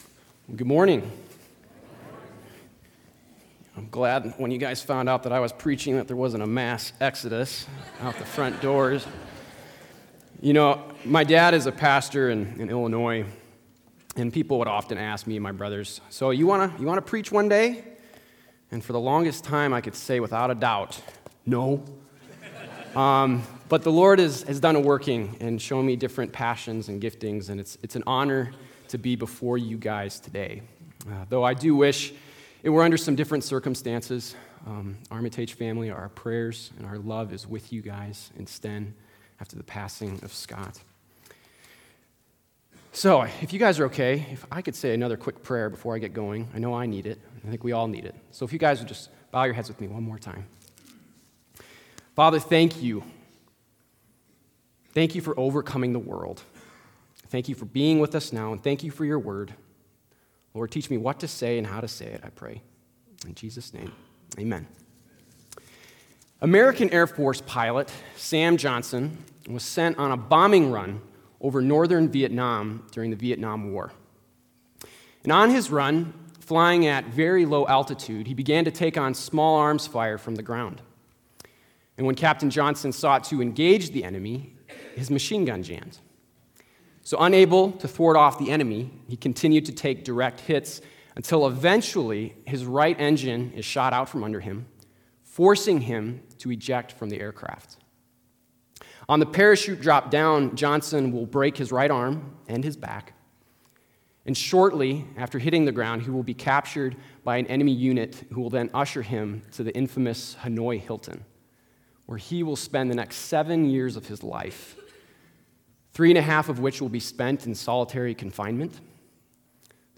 Sermon4.7.19.mp3